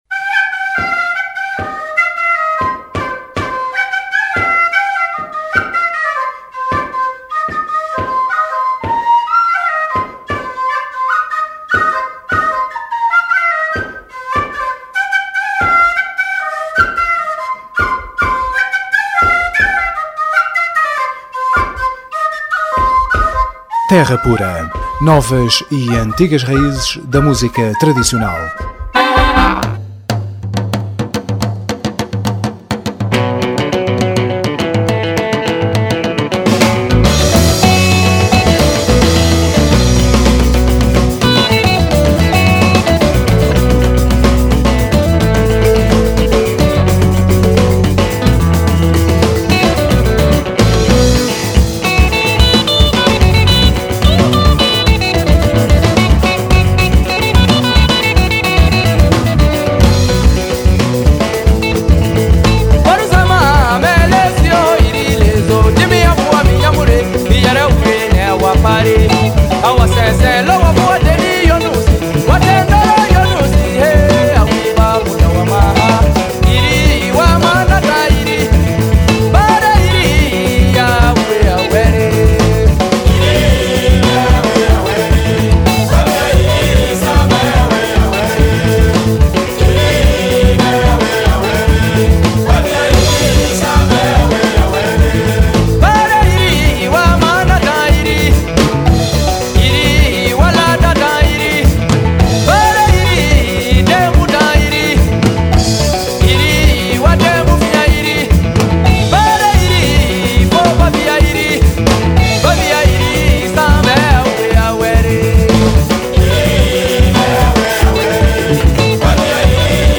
sonoridades músicas do mundo